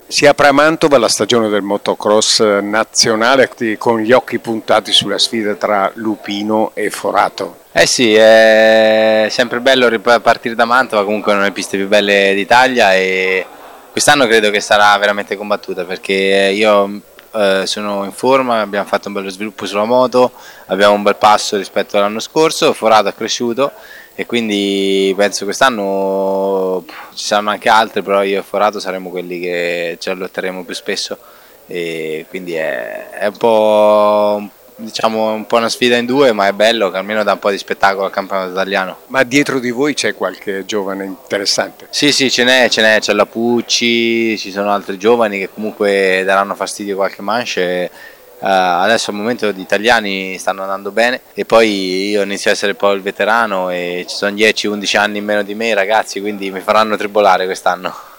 raccogliendo le dichiarazioni degli attori principali: